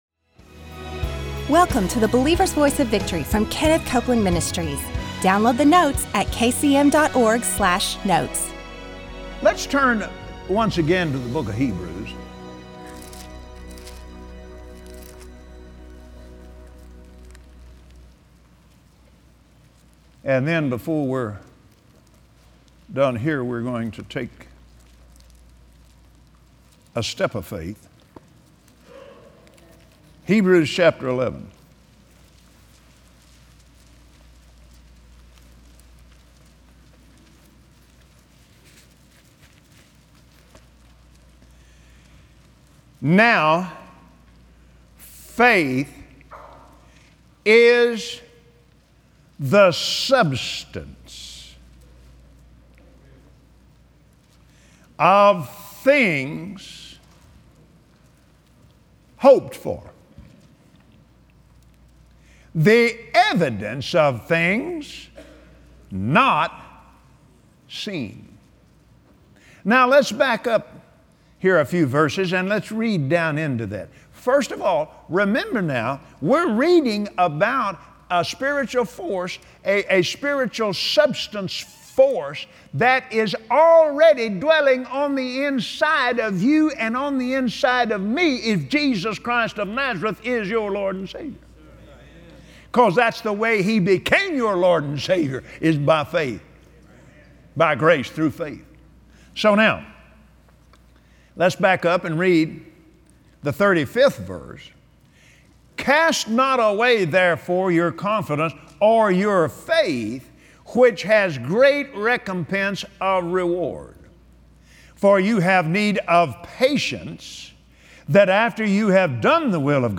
Kenneth Copeland continues his study on the foundations of faith today. Learn the keys to living a victorious life of faith.